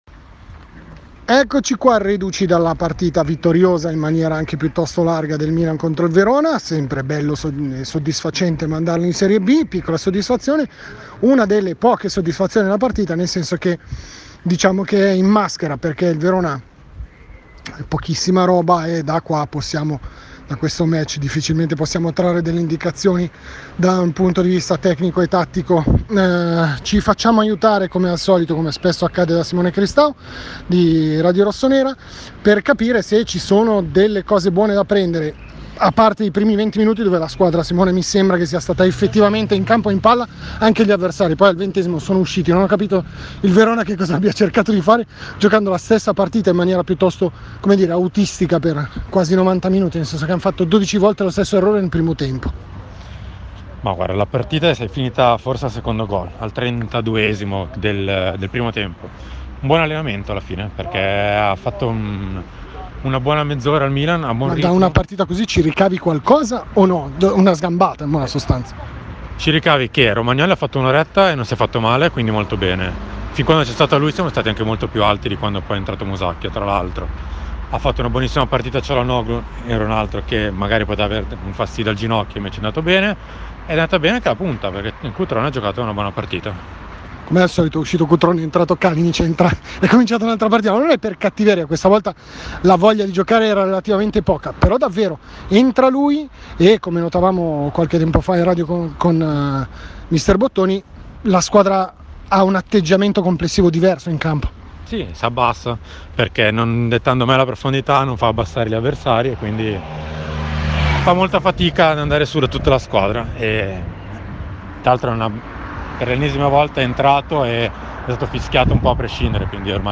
Commento audio a fine gara da San Siro